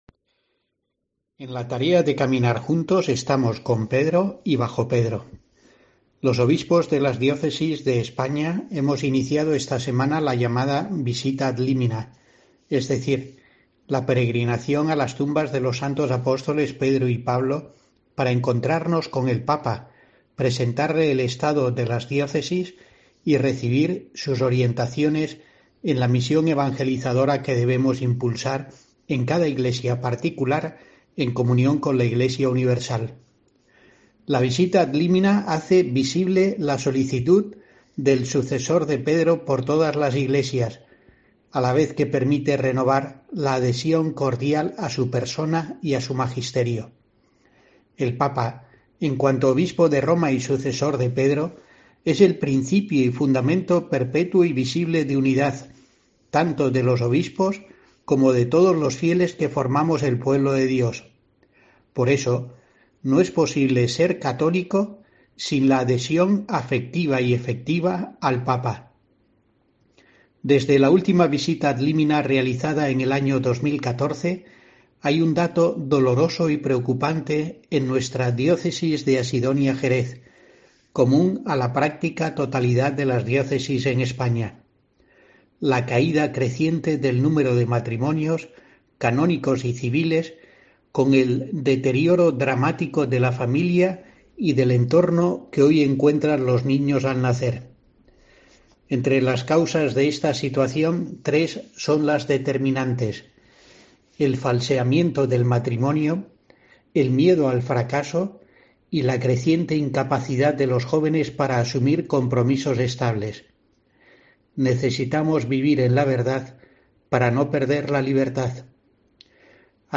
Escucha aquí la reflexión semanal de monseñor José Rico Pavés para los oyentes de COPE del 17-12-21